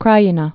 (krīē-nə)